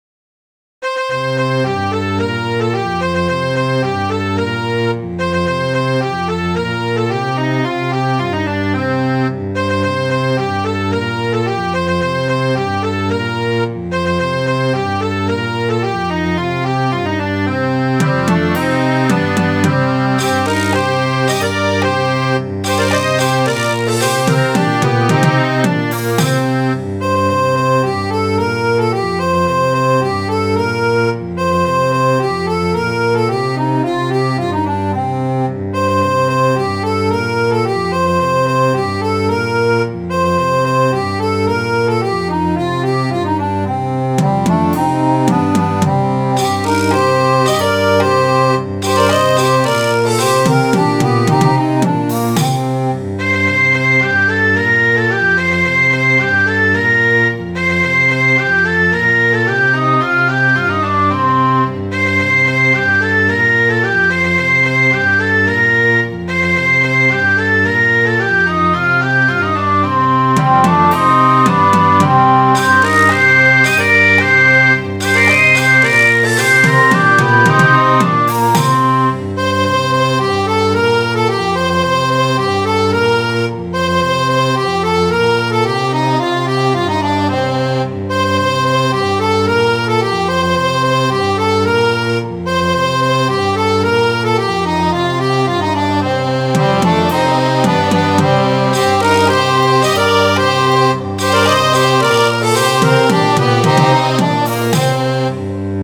Midi File, Lyrics and Information to The Bonny Ship The Diamond